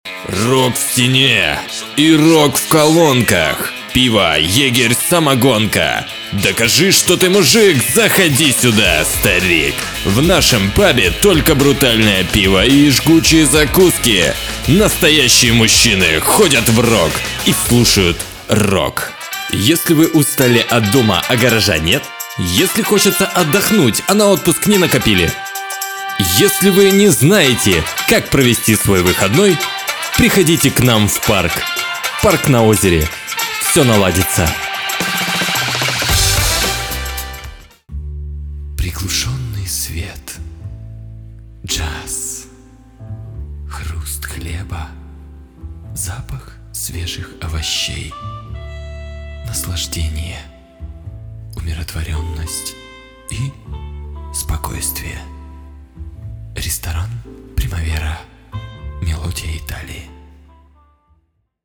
Пример звучания голоса
Муж, Рекламный ролик/Молодой
Звуковая карта - Native instrument KOMPLETE Audio 6 Предусилитель - BEHRINGER HA 400 MICROAMP Микрофон - Behringer B-1 Звукопоглощающая комната.